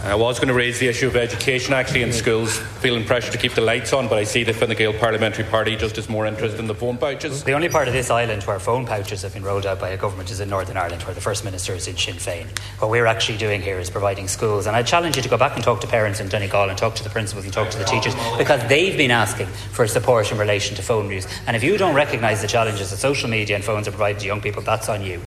During questions in the Dail this week, Sligo, Leitrim and South Donegal Deputy Frank Feighan  asked about the controversial phone pouch scheme, prompting Donegal TD and Sinn Fein Finance Spokesperson Pearse Doherty to comment that Fine Gael seem more interested in smartphones than in the impact of the cost of living on schools.
Tanaiste Simon Harris was quick to hit back…………..